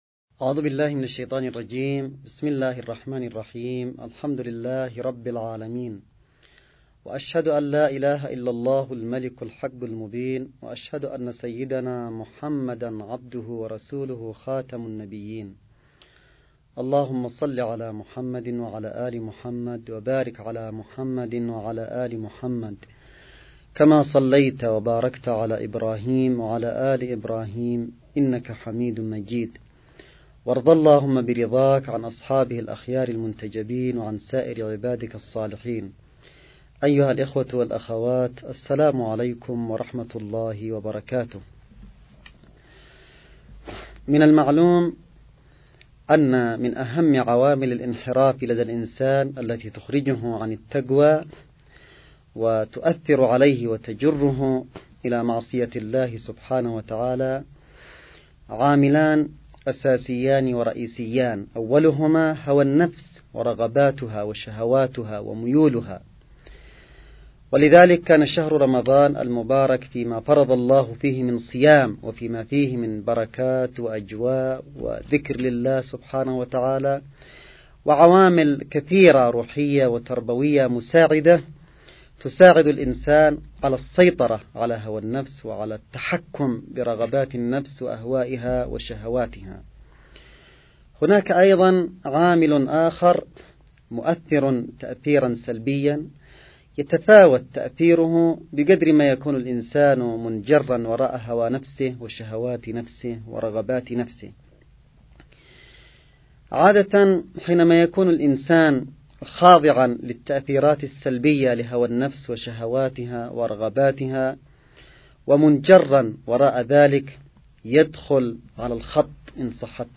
نص +استماع للمحاضرة الرمضانية الرابعة (إن الشيطان لكم عدو) للسيد عبد الملك بدر الدين الحوثي
محاضرة_السيد_عبدالملك_بدر_الدين3.mp3